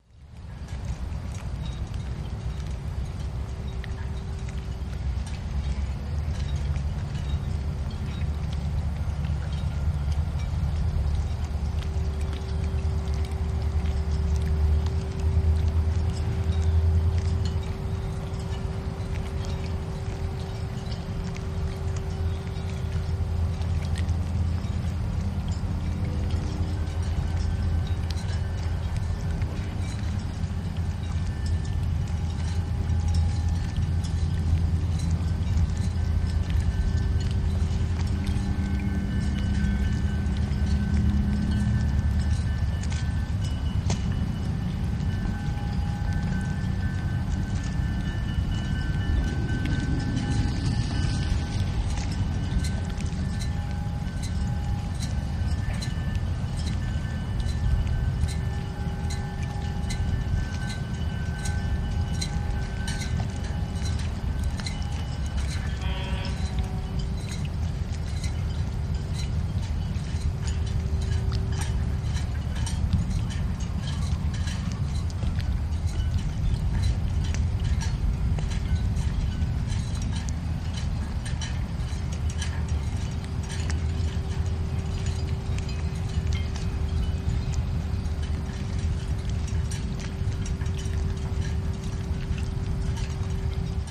Harbor Background General